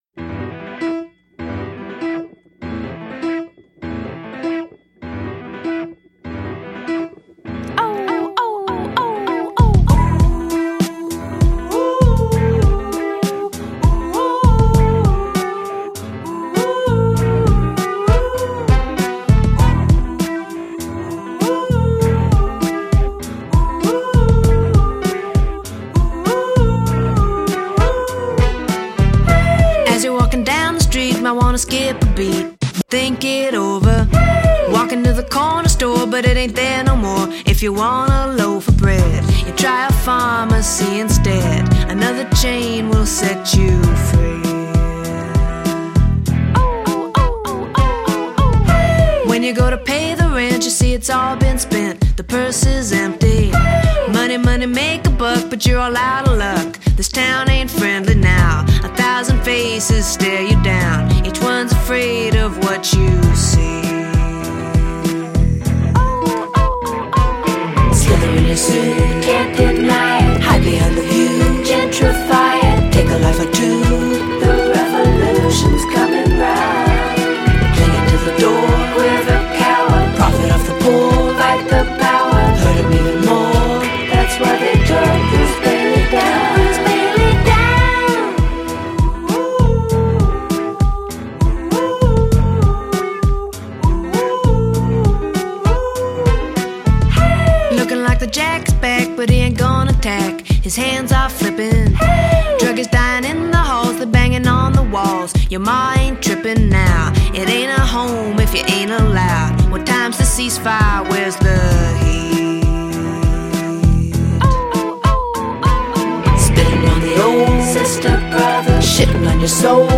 She’s clearly in a pissed off mood here.